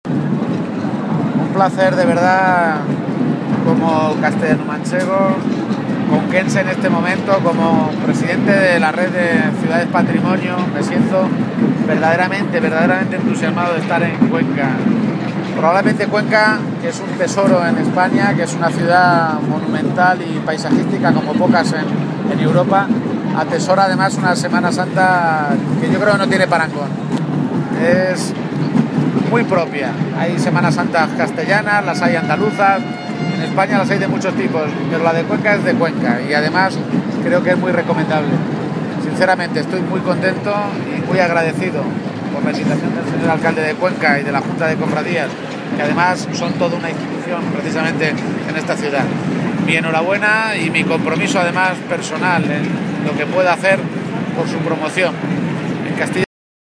Durante su atención a los medios de comunicación, en el Ayuntamiento de Cuenca, junto a su alcalde, Juan Ávila, Page, que también es el Presidente del Grupo de Ciudades Patrimonio de la Humanidad en su condición de alcalde de Toledo, ha expresado su alegría y satisfacción por poder participar de una procesión “asombrosa y muy bella” como la que recorría las calles de Cuenca desde esta pasada madrugada.